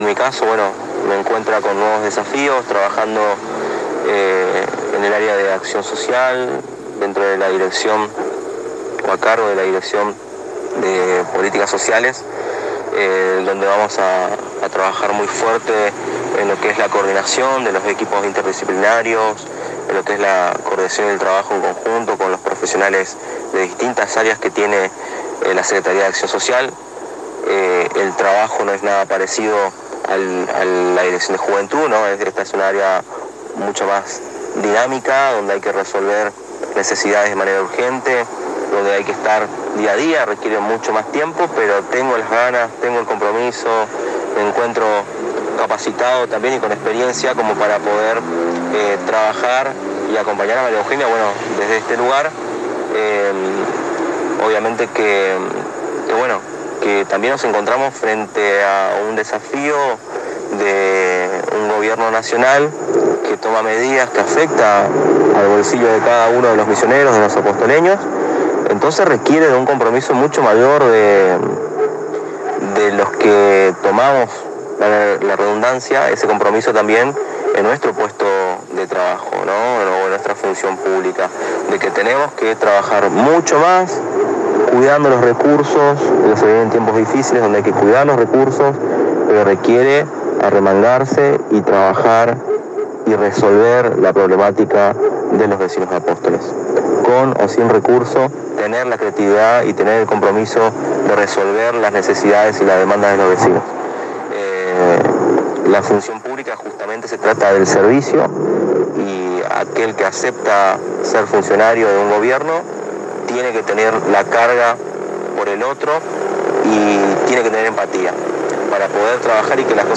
En diálogo exclusivo con la ANG Muñoz resaltó la combinación de experiencia y juventud en la conformación del Gabinete Municipal y que el personalmente se encuentra con todas las ganas de trabajar con ingenio, creatividad, empatía para acercar soluciones a los ciudadanos sin perder de vista el cuidado de los recursos.